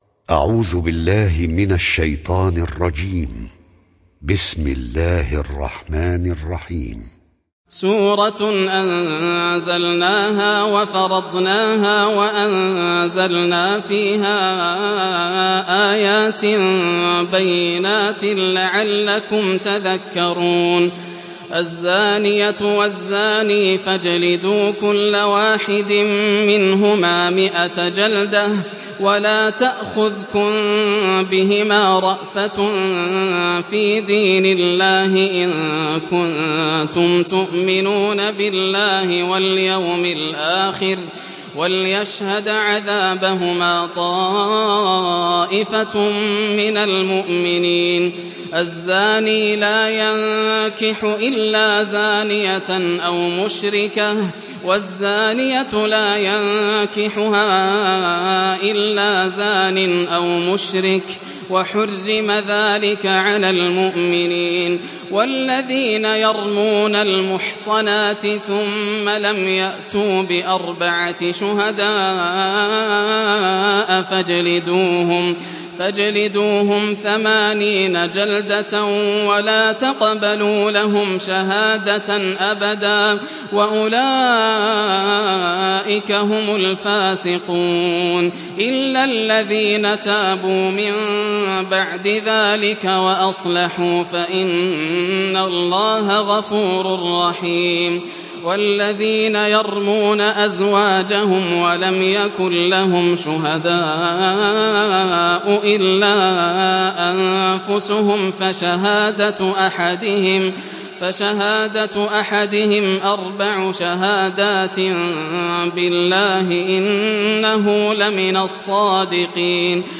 Surat An Nur mp3 Download Yasser Al Dosari (Riwayat Hafs)